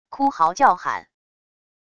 哭嗥叫喊wav音频